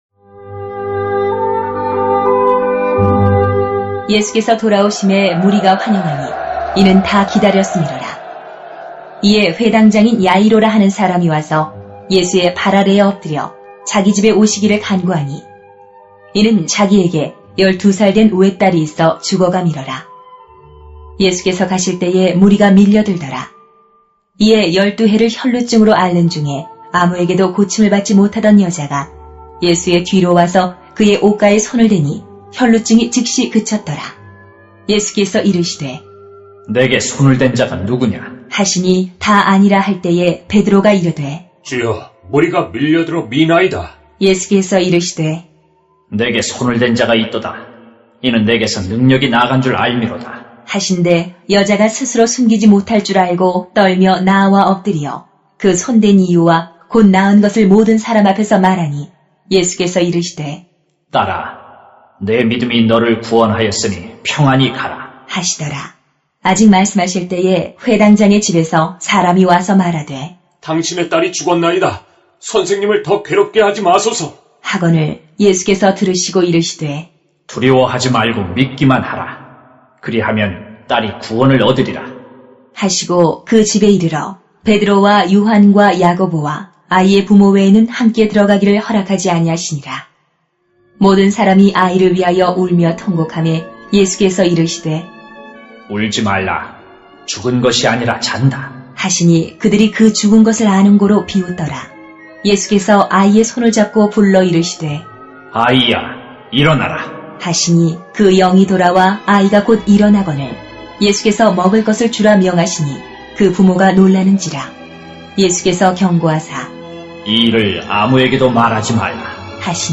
[눅 8:40-56] 더 큰 문제 앞에서는 더 큰 믿음이 필요합니다 > 새벽기도회 | 전주제자교회